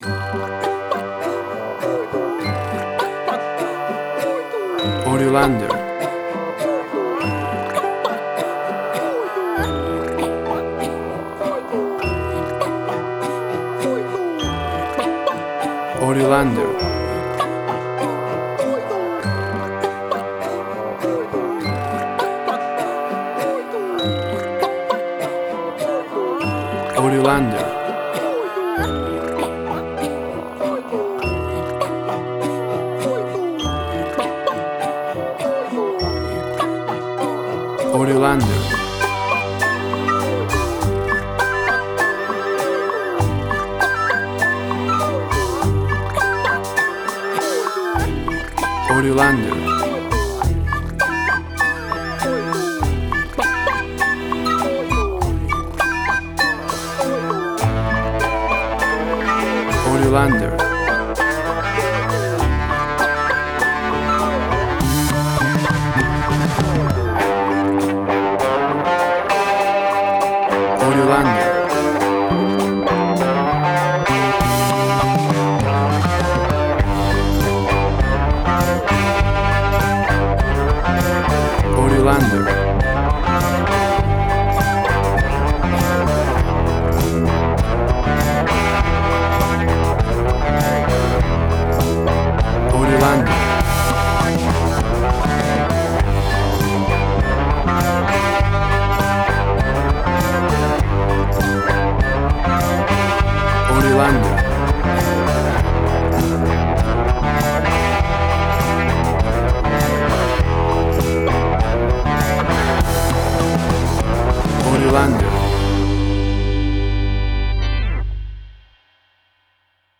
Fantasy and bizarre music with fantastic animals sounds
Tempo (BPM): 102